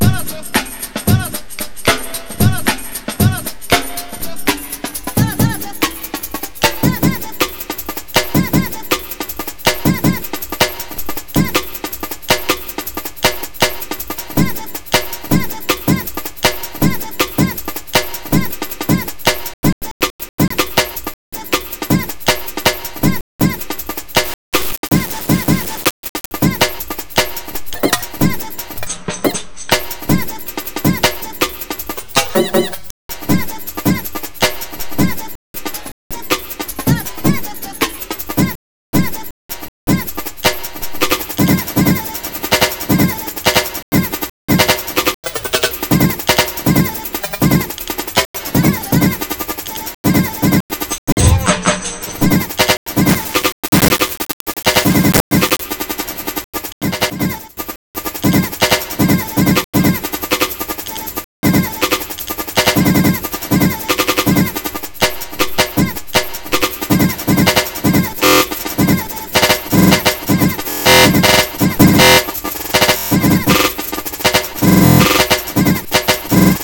A few examples of sliced audio this patch can make (made those on the first take without any external editing):